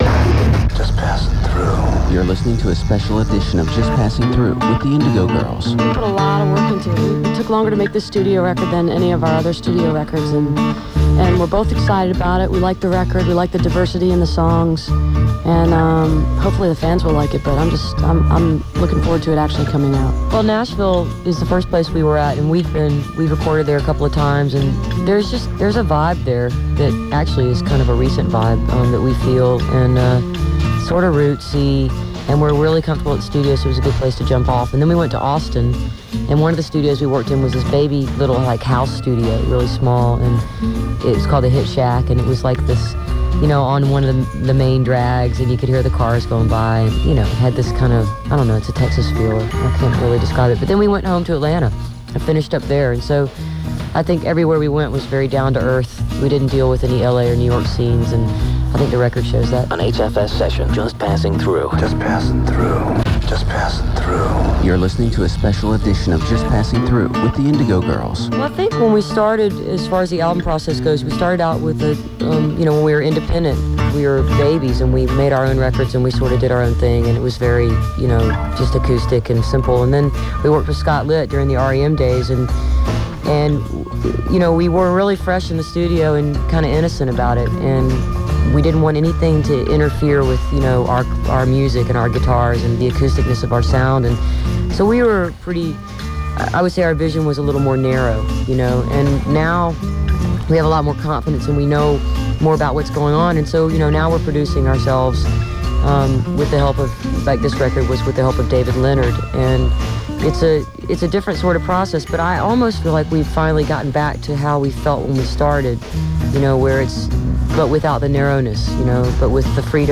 lifeblood: bootlegs: 1997-04-20: whfs radio program - rockville, maryland
07. interview (2:10)